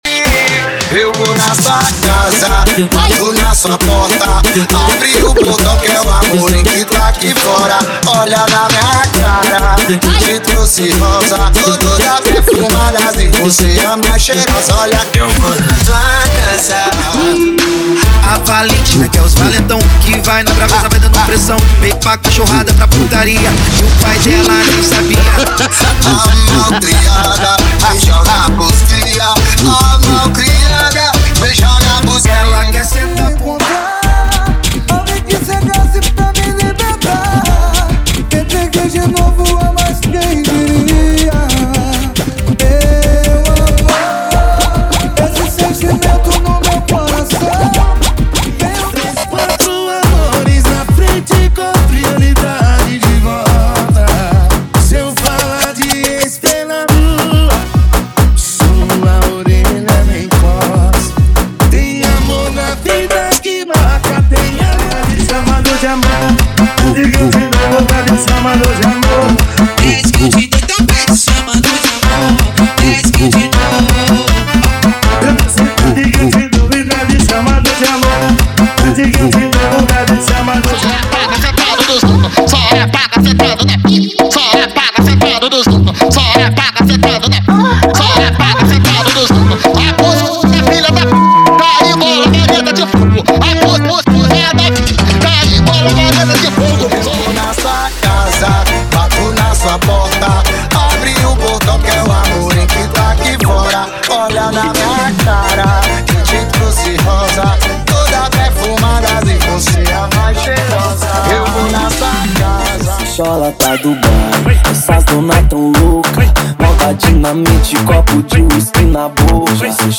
• Funk Light e Funk Remix = 105 Músicas
• Sem Vinhetas
• Em Alta Qualidade